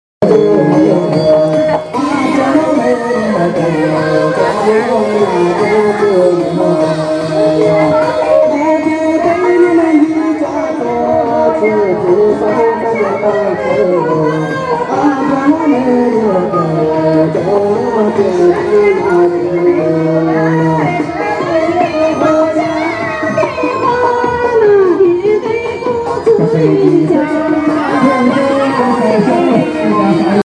???????????????????????????????a random Santa Claus,???????????????????????????????and as usual, there is always a game of checkers to stand around and watch:???????????????????????????????In the corner of one temple square, there were two crowds of people in close proximity trying to listen to separate singers:???????????????????????????????Click here to watch the Competing Singers, or here to listen to
Singers.mp3